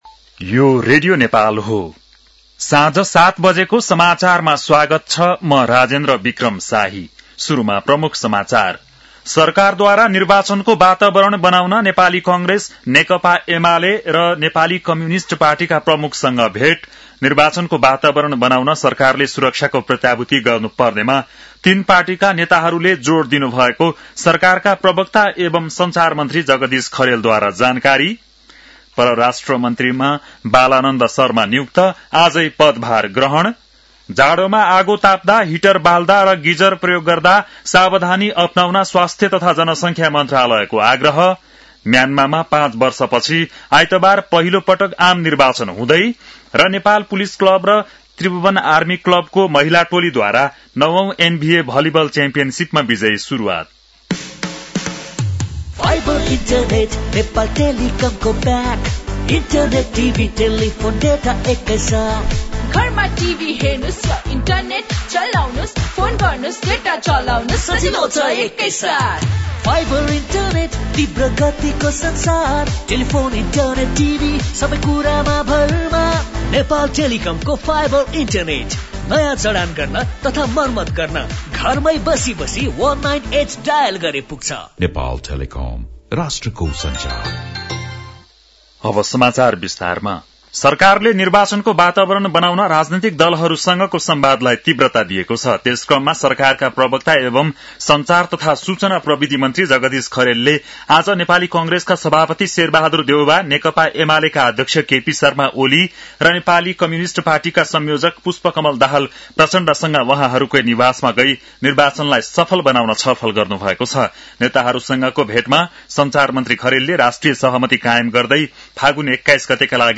An online outlet of Nepal's national radio broadcaster
बेलुकी ७ बजेको नेपाली समाचार : ११ पुष , २०८२
7-pm-nepali-news-9-11.mp3